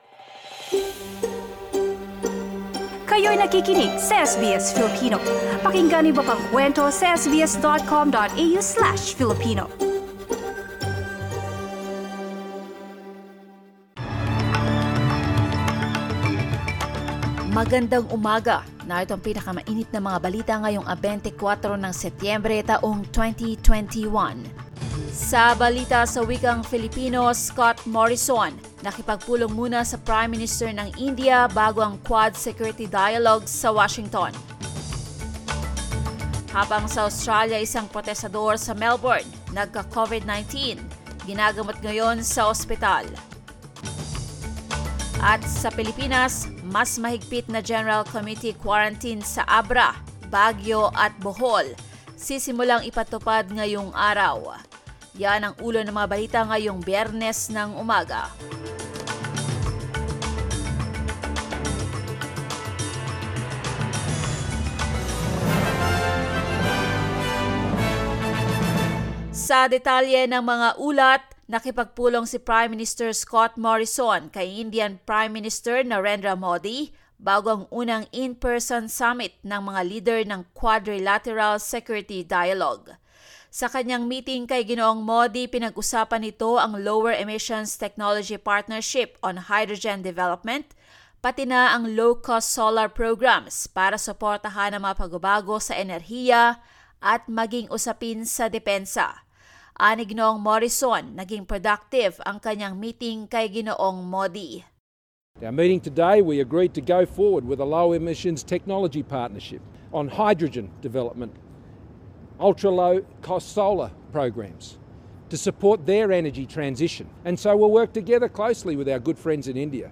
SBS News in Filipino, Friday 24 September